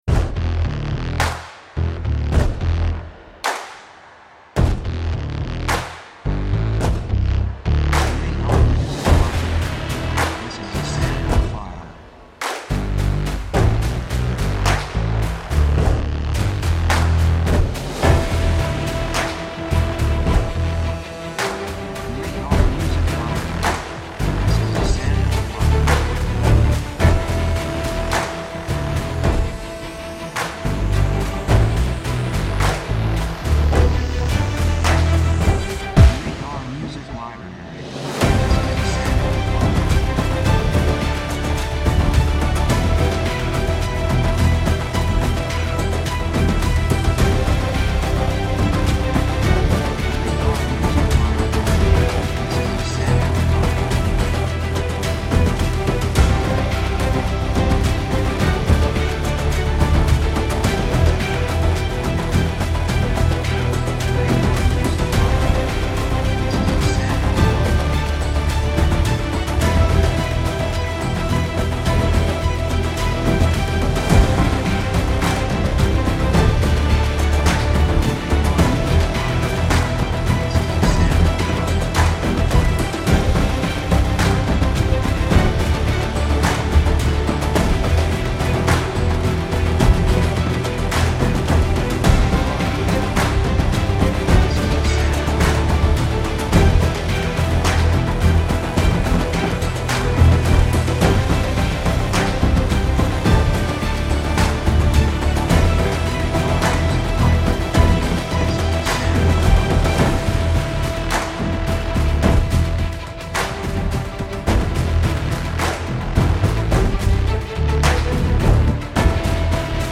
雰囲気激しい, 壮大, 決意, 喜び
曲調ニュートラル
楽器オーケストラ, パーカッション, ボーカル, 手拍子
サブジャンルシネマティック, オーケストラハイブリッド
テンポやや速い